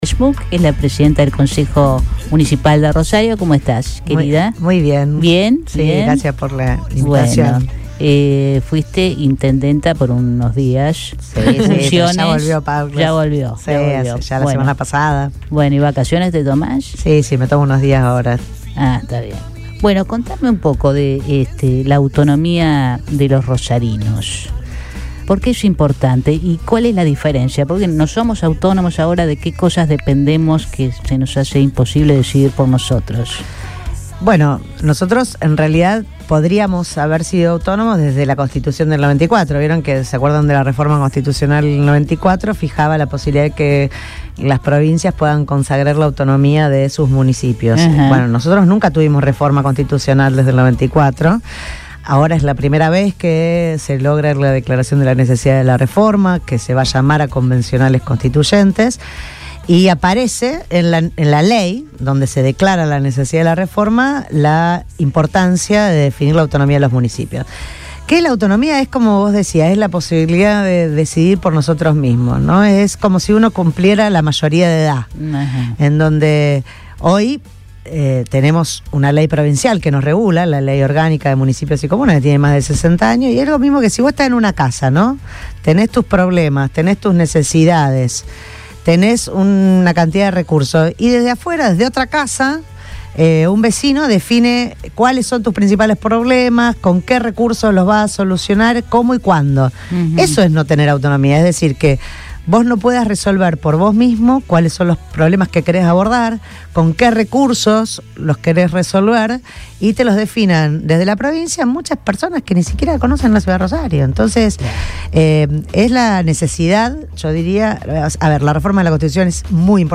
María Eugenia Schmuck, presidenta del Concejo Municipal de Rosario, visitó los estudios de Radio Boing para dialogar con el equipo de Todo Pasa sobre un tema crucial para el futuro de la ciudad: la necesidad de consagrar la autonomía de los municipios. En el contexto de la próxima reforma constitucional en Santa Fe, Schmuck explicó por qué este cambio representa una oportunidad histórica para Rosario.